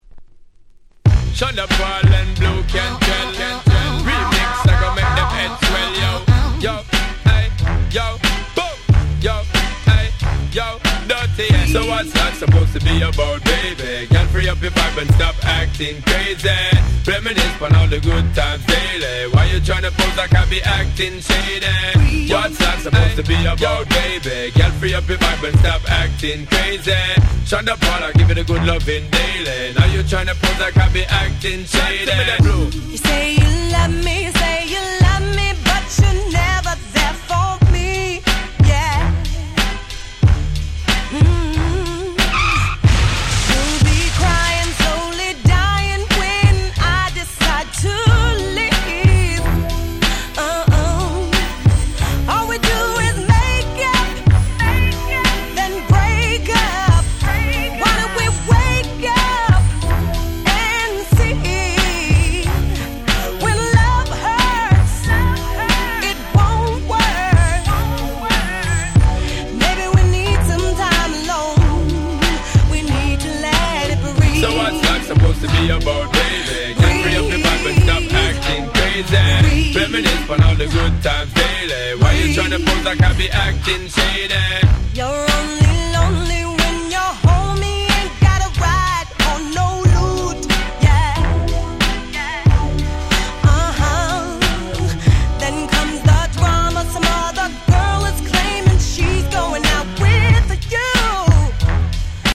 03' Smash Hit R&B !!